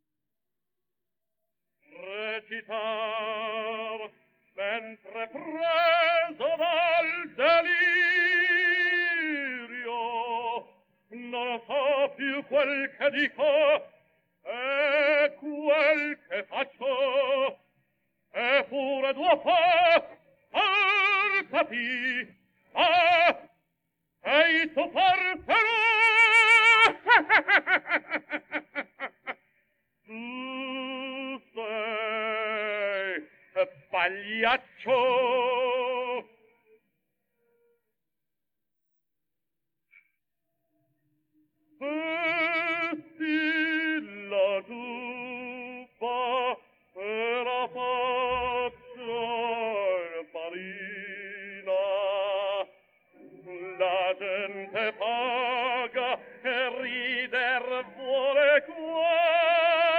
Index of /publications/papers/dafx-babe2/media/restored_recordings/caruso_giubba